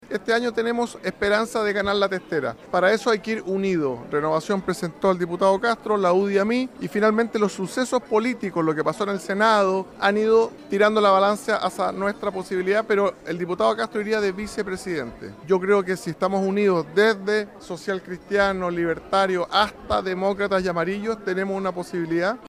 En conversación con La Radio desde Nueva Delhi, Alessandri dijo que la fórmula sería que Castro vaya como vicepresidente en su terna y además de llamar a la unidad, dice que “los sucesos del Senado” han inclinado la balanza a su favor.